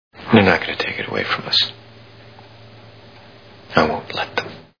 Minority Report Movie Sound Bites